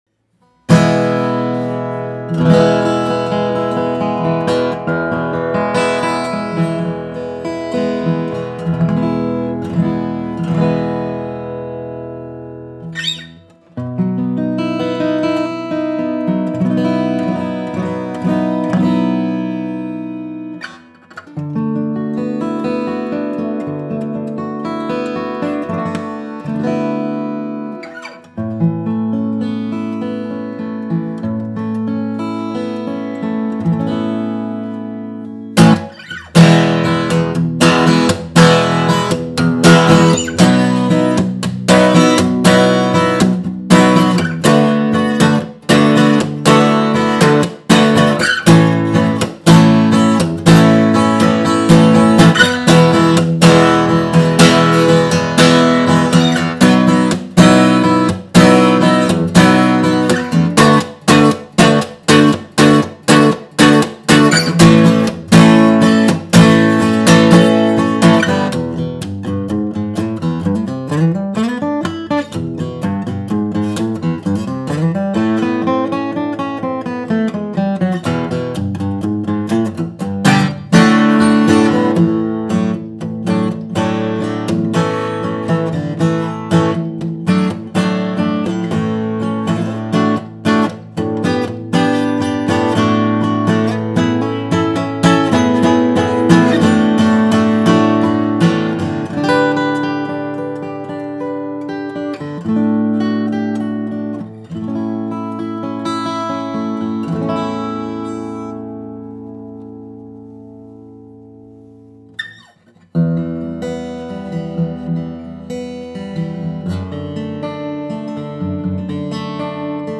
The Sitka top aged well and delivers a warm tone. She has excellent volume (it’s still a dread), but with a slightly more focused sound compared to forward-shifted bracing. Notes feel a touch quicker and more direct. Her overtones come with a wonderful harmonic richness. it’s the classic Martin dreadnought tone. Rich and full, but with a tighter low end and a bit more balance across the spectrum.